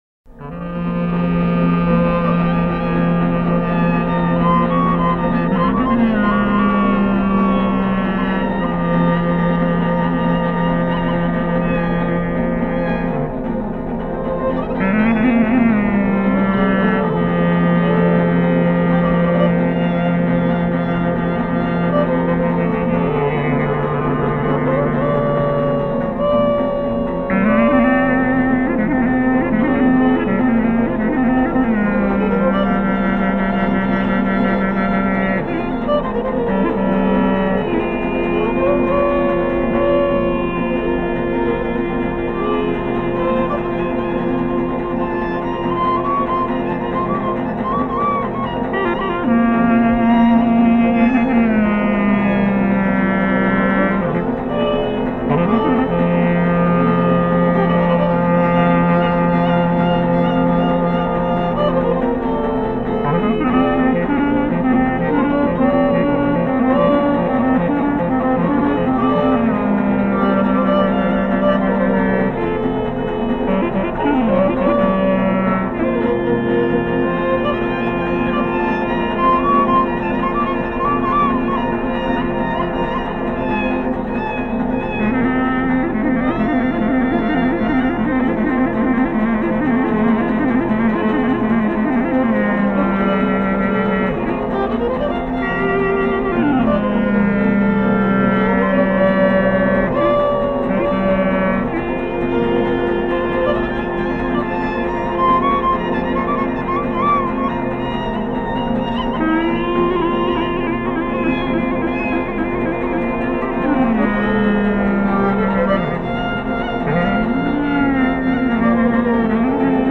Në këtë kaba të natyrës baritore vihet re një balancim perfekt mes rolit të gërnetës si marrës dhe violinës si kthyese, e cila herë pas here rivendos raporte të reja mes marrësit dhe kthyesit, duke i dhënë përparësi kthyesit.